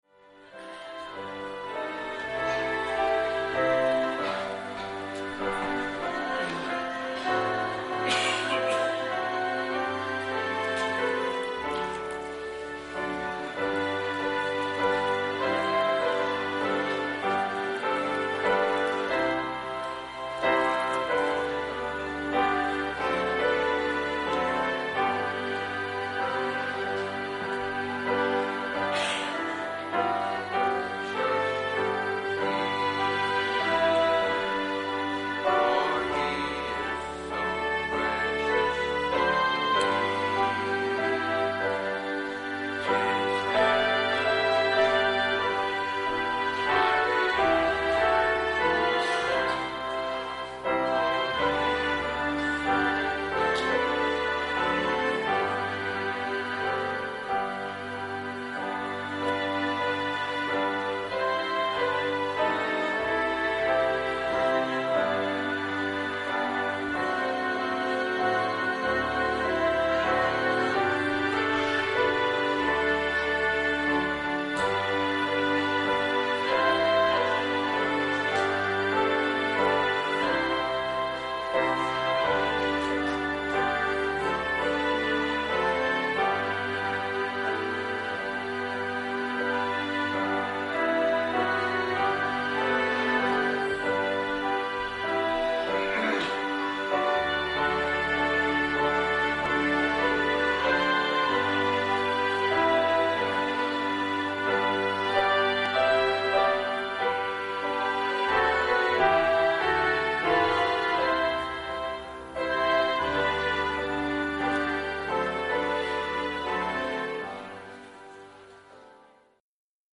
Morning message from 1 Timothy 4:1-10.